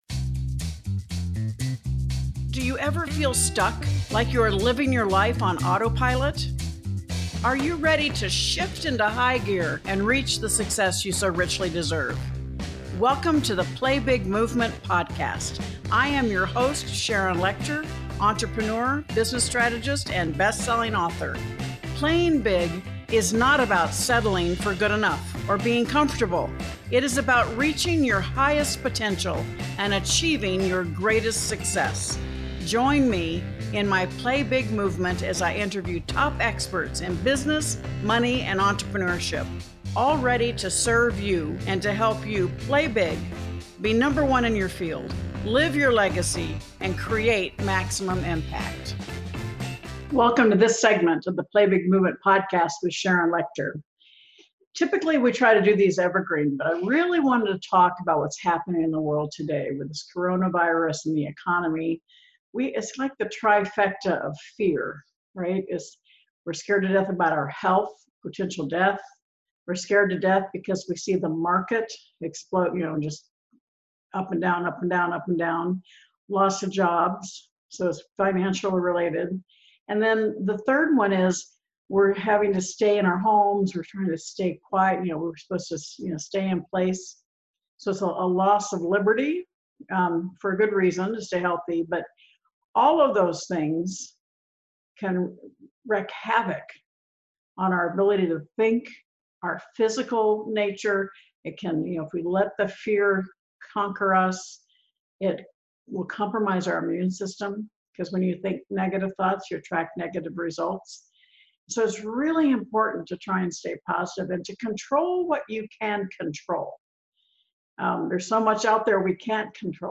This week is a solo episode to talk about something I feel is very important as we all navigate the coronavirus pandemic, and it’s some of the core teachings from …